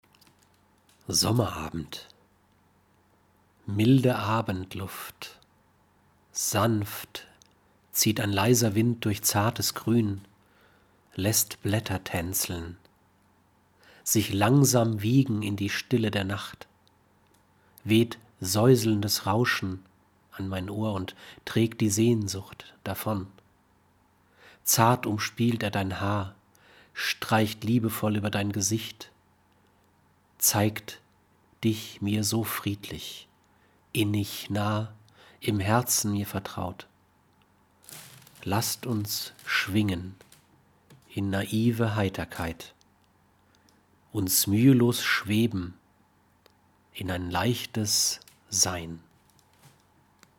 Deutscher Sprecher, tief, Kinderbuch, Sachtext, Voice-Over, Lyrik, Roman, Vorleser Arne Dahl, MP3-Home-Production (ZOOM), 6 deutsche Dialekte
Sprechprobe: Sonstiges (Muttersprache):